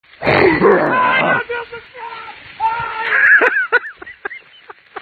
cara-leva-o-maior-susto-na-mata-audiotrimmer.mp3